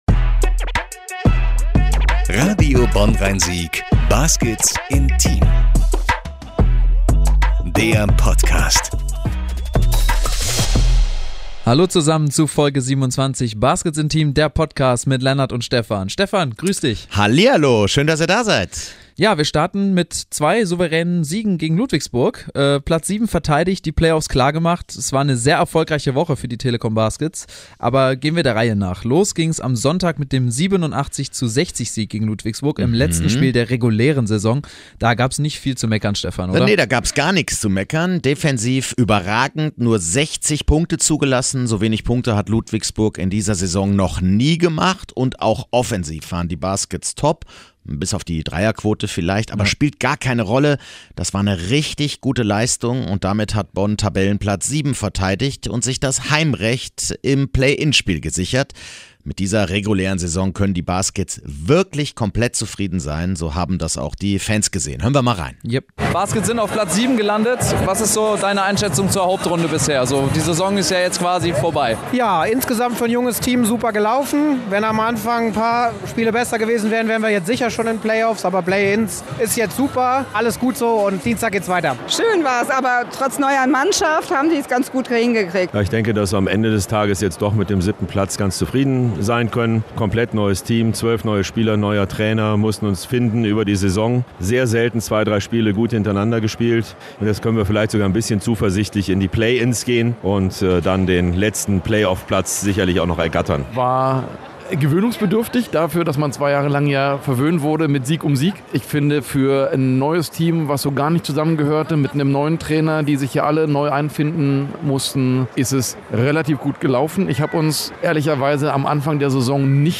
Stolze Fans dürfen da natürlich auch nicht fehlen.